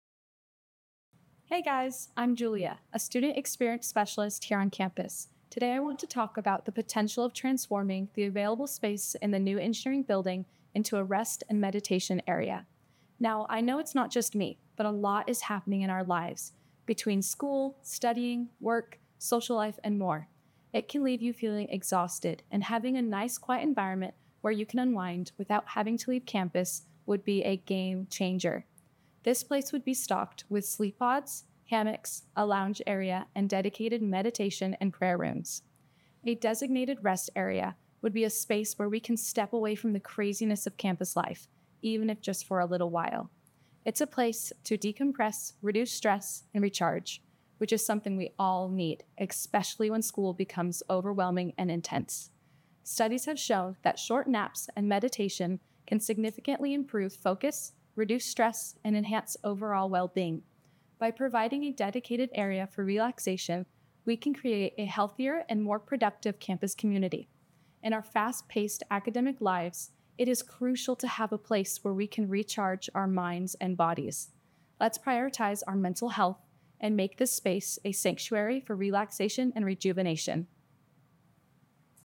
deepfake-real-audio.mp3